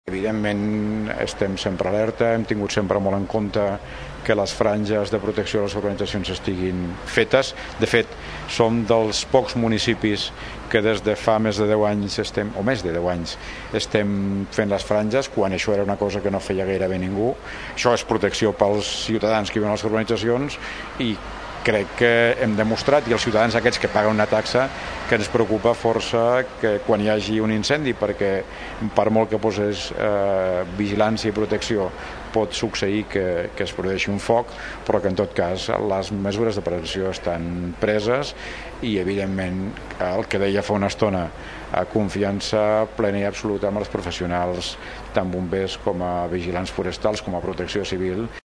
Ho explica l’alcalde de Tordera, Joan Carles Garcia, al programa de Ràdio Tordera “Línia directa amb l’Alcalde”. També expressava la seva total confiança en els professionals que vetllen per la seguretat dels boscos del municipi.